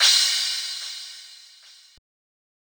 crash1.wav